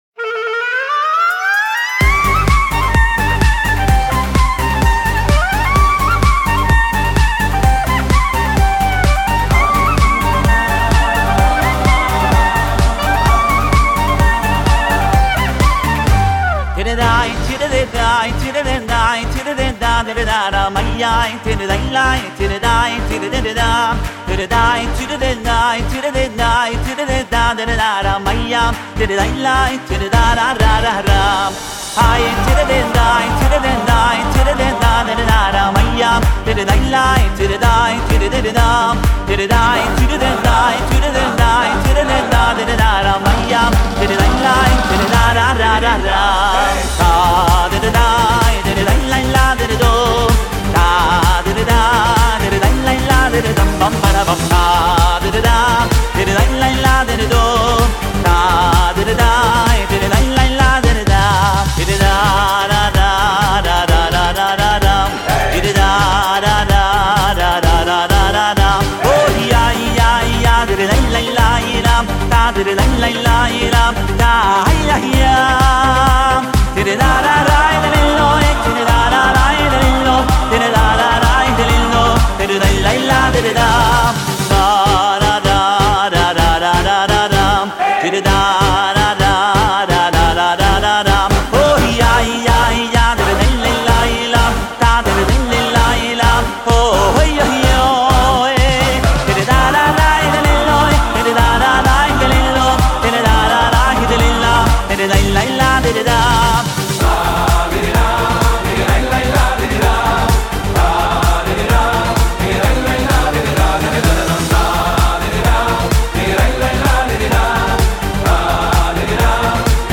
קלרינט